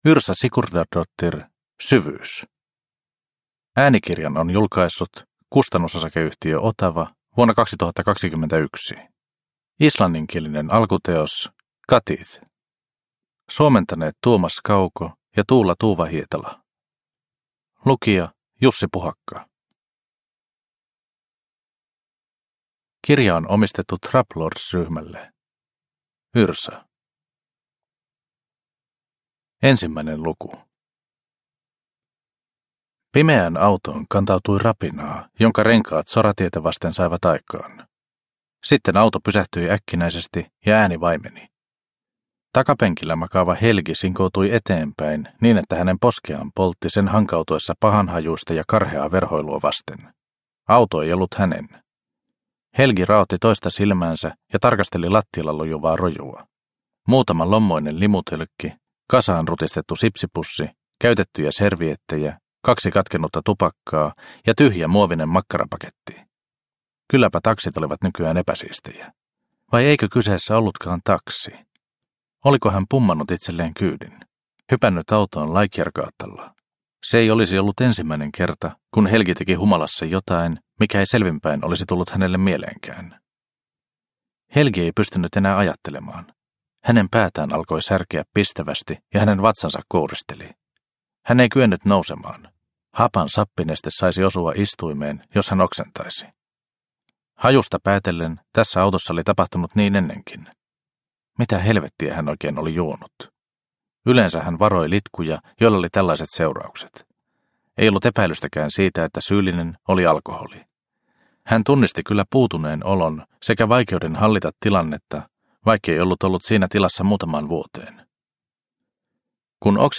Syvyys – Ljudbok – Laddas ner